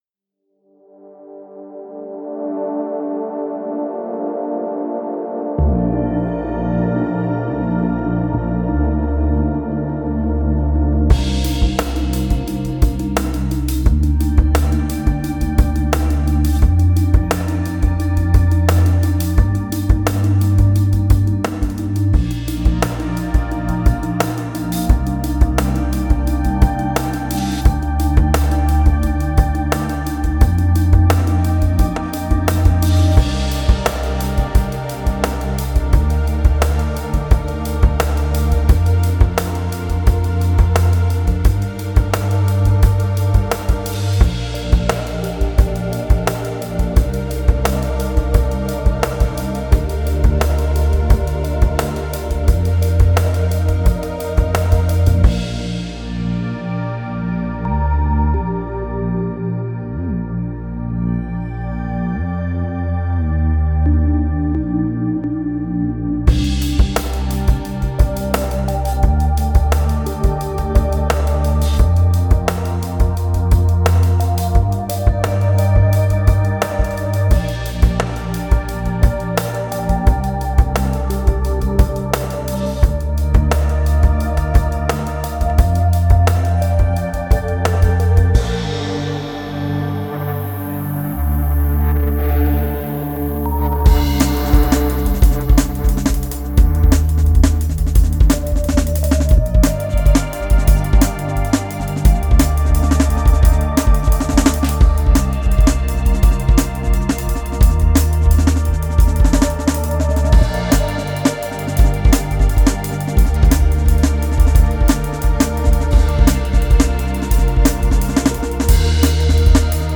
Drums Recording Pop Electronic Rock